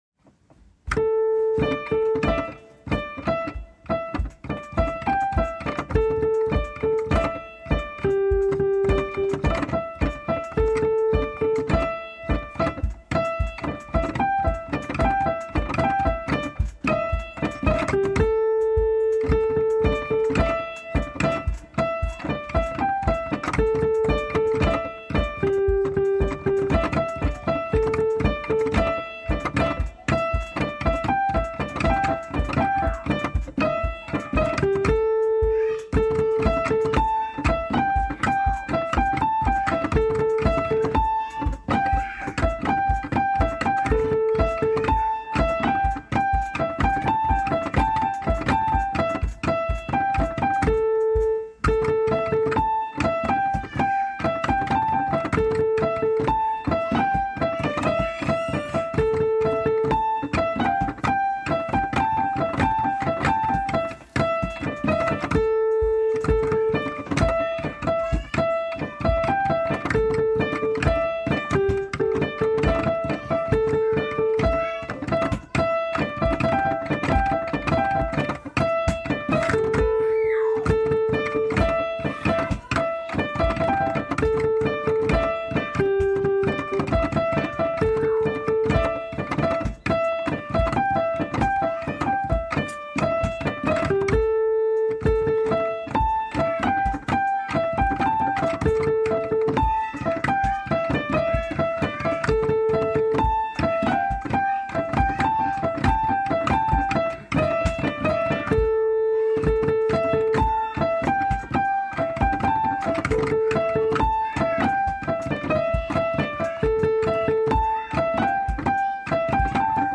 (piano, mp3)